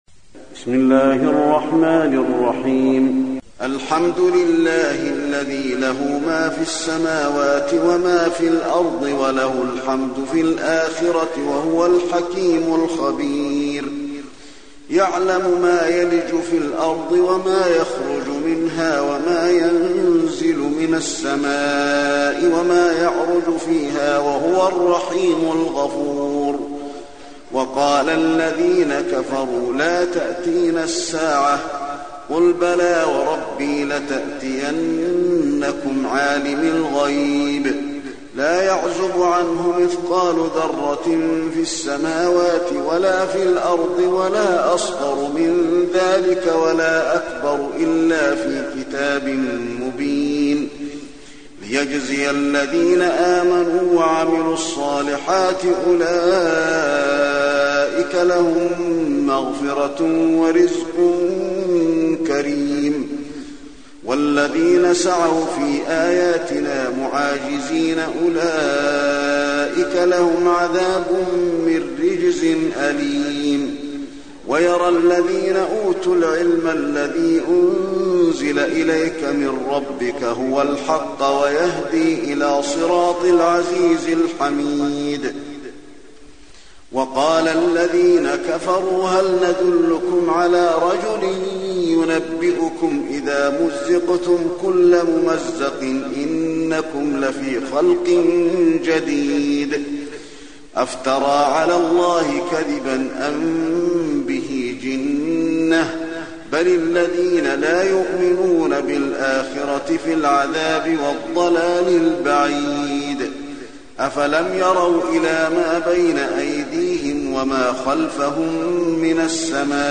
المكان: المسجد النبوي سبأ